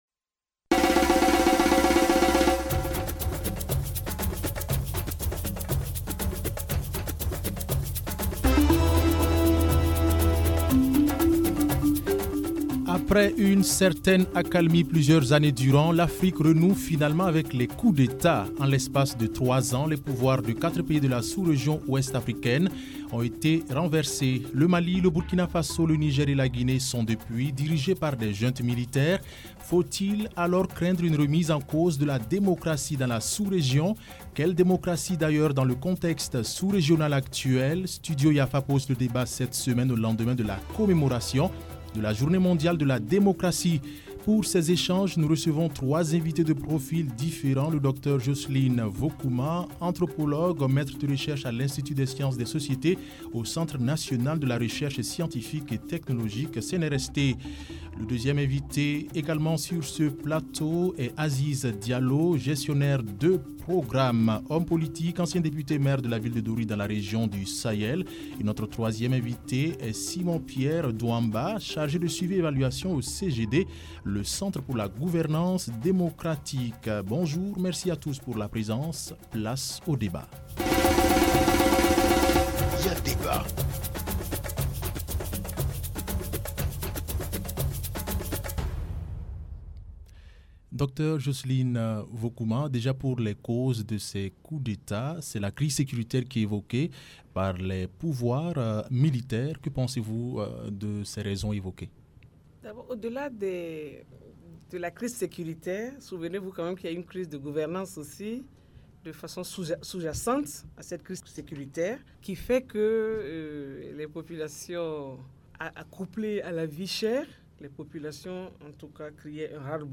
Studio Yafa pose le débat. Autour de la table, trois invités de profils différents. Une anthropologue, un politicien et un acteur de la société civile.